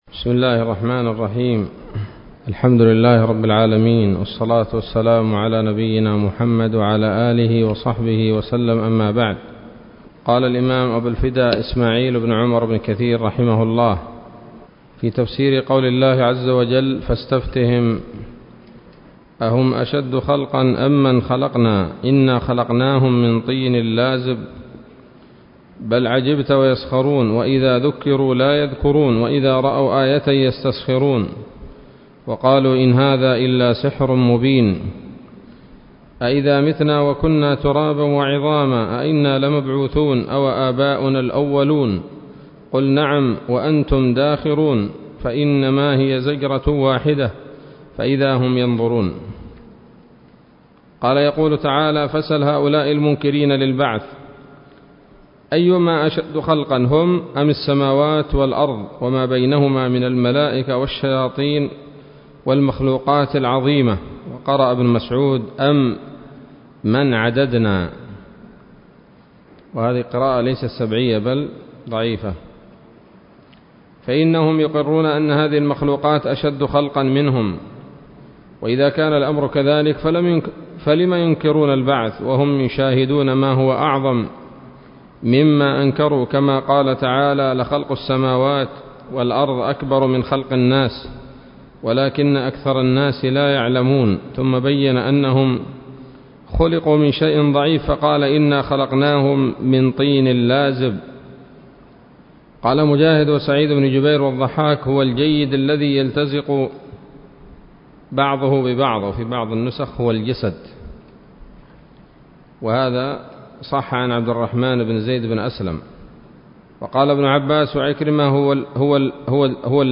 الدرس الثاني من سورة الصافات من تفسير ابن كثير رحمه الله تعالى